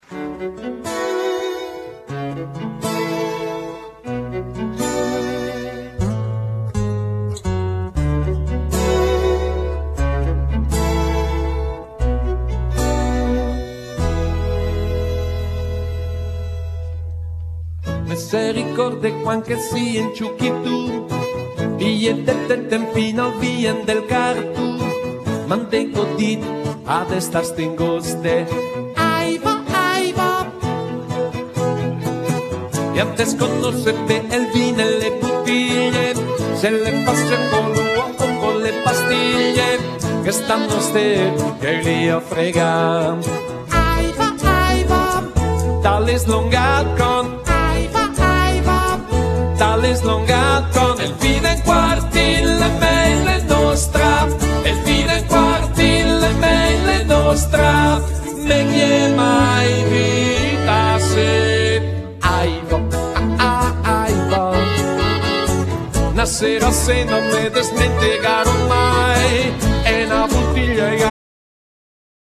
Genere : Folk